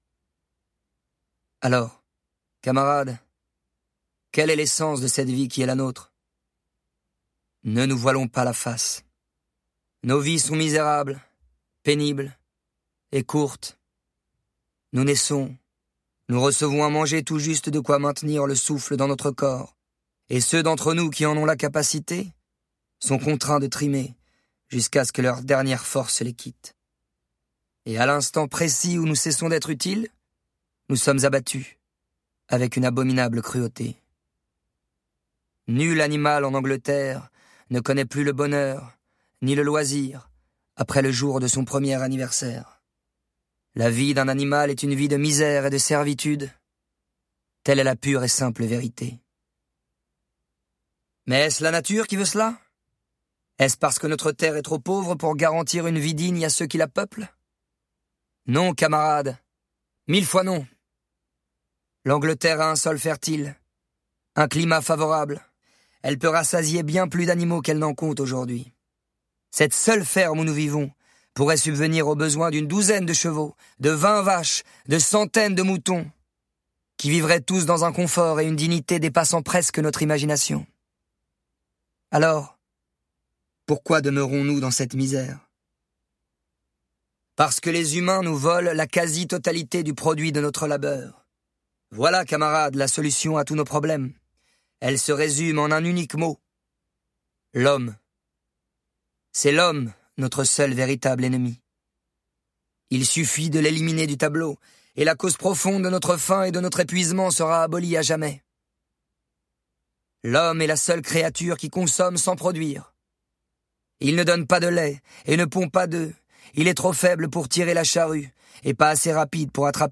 Extrait gratuit - La Ferme des animaux de George Orwell, George Orwell
Le classique de George Orwell lu par Arthur Teboul, la voix de Feu! Chatterton